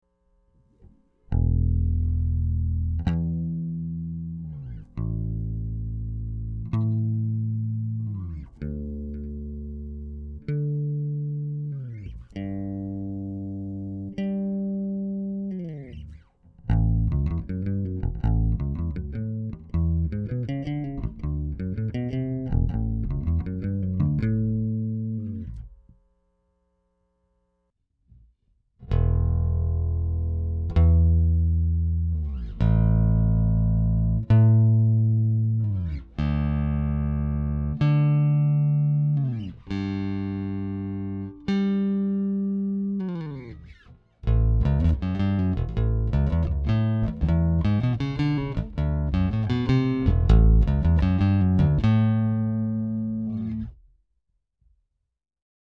Here's a straight to the board comparison between my old Fender Squire Jaguar and my new Ibanez SR250...